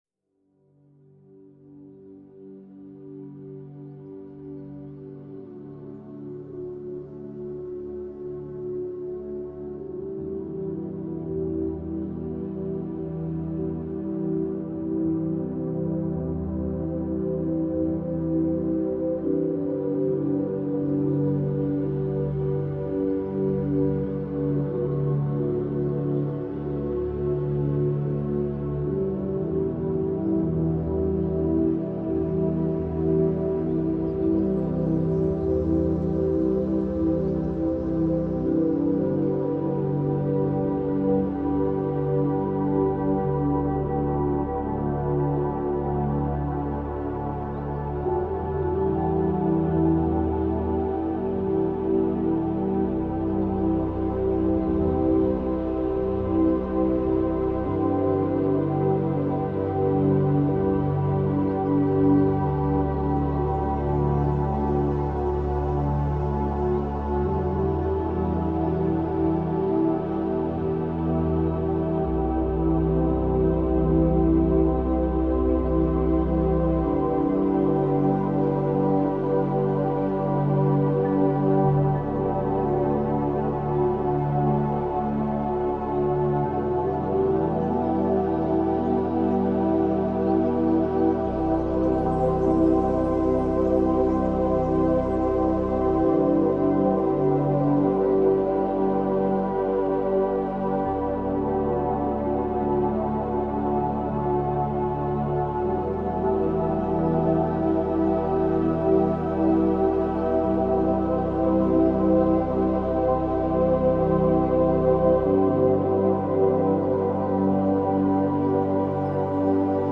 CHANTS VIBRATOIRES